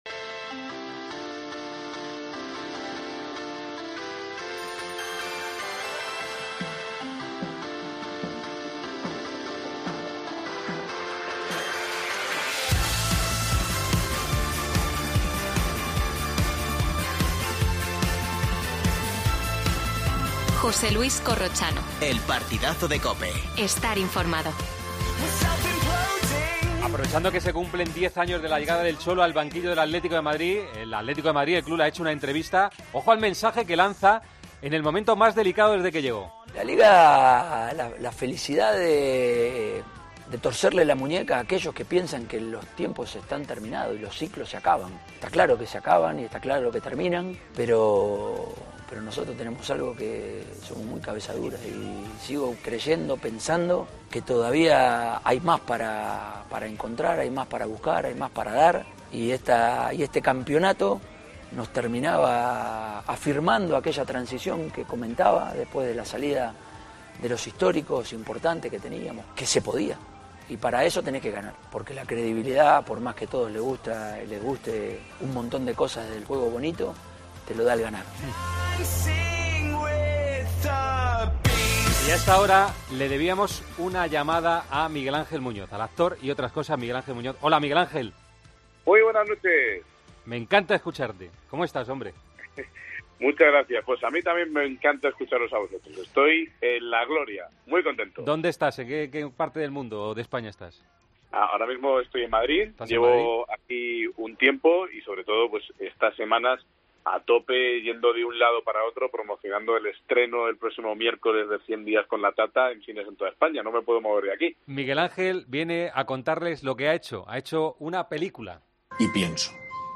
AUDIO: Entrevista a Miguel Ángel Muñoz, que estrena "100 días con la Tata". Simeone cumple diez años en el Atleti. Actualidad del Real Madrid.